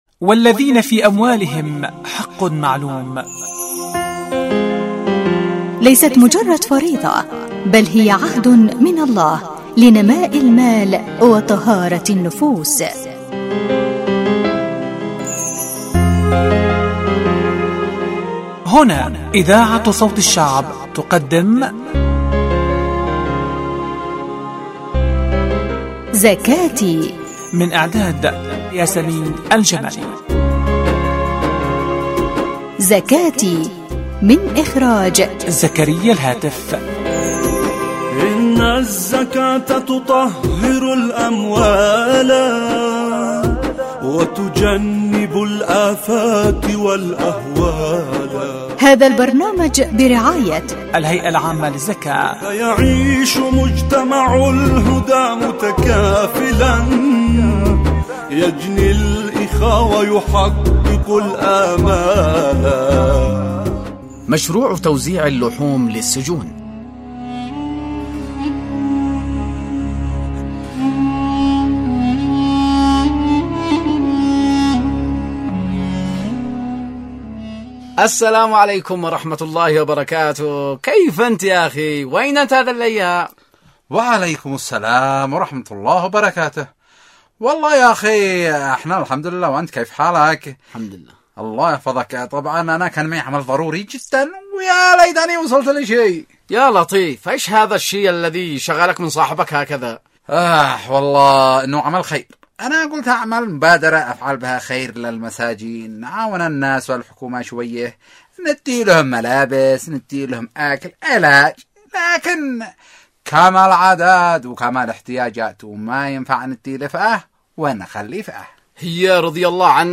البرامج الحوارية زكاتي زكاتي ح16